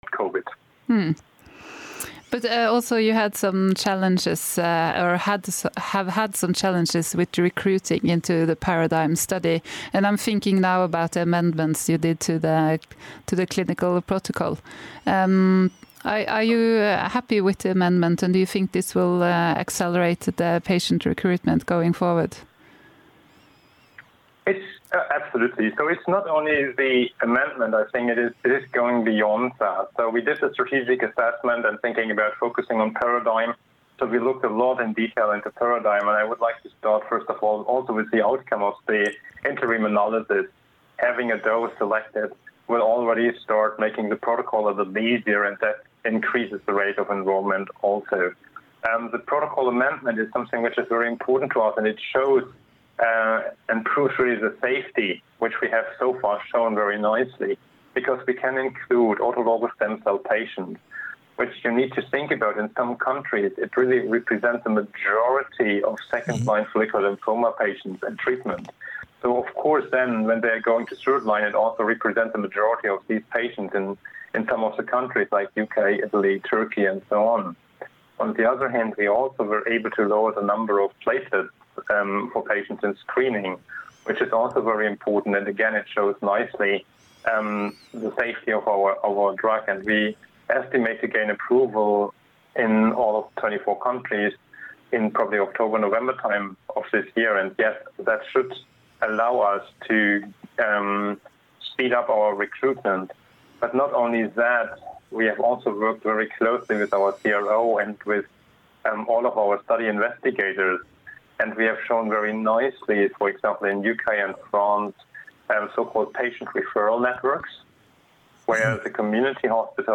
Det var faktisk noe av det jeg likte best med hele intervjuet.